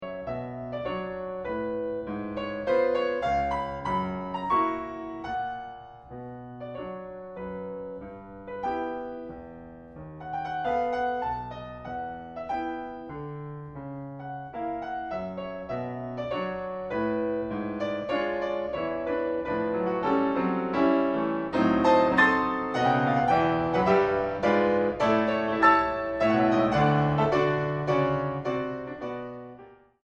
En si menor. Tempo giusto.